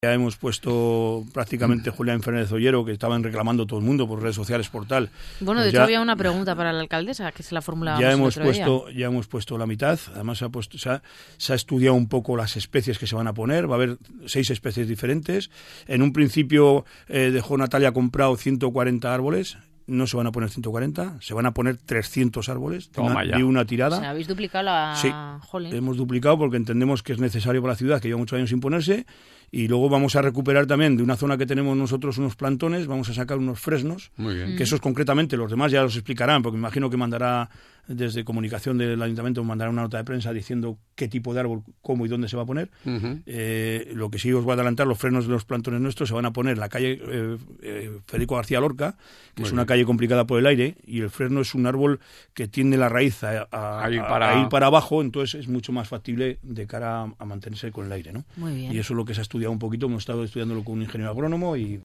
Según ha destacado el concejal de `POR LA RIOJA´, miembro del equipo de gobierno, los trabajos han comenzado por la calle Julián Fernández Ollero.